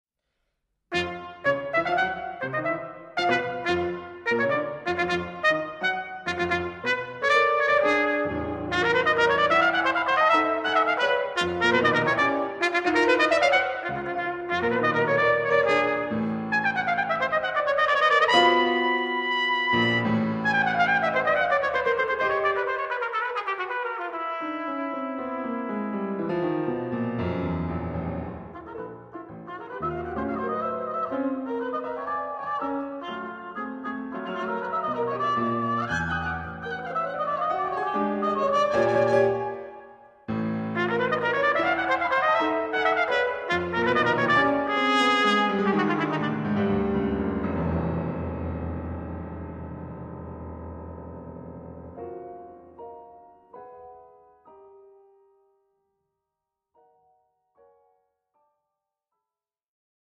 Instrumentalnoten für Trompete